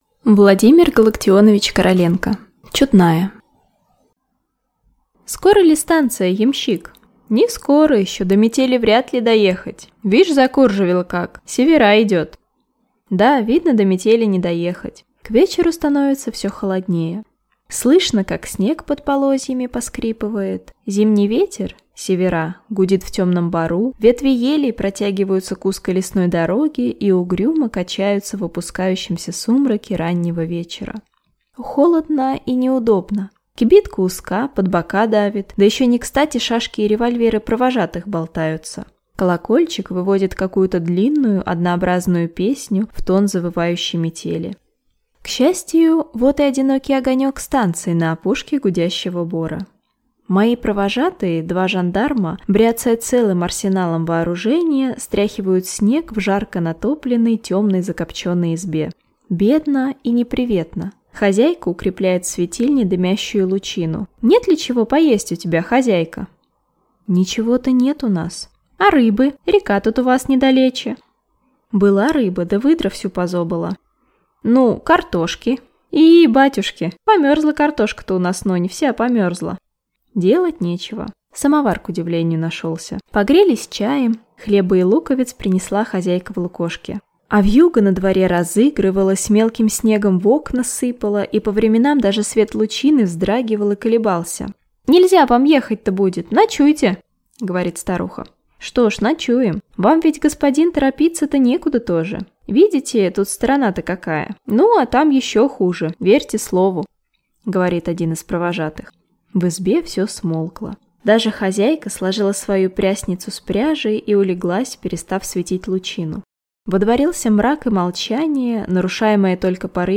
Аудиокнига Чудная | Библиотека аудиокниг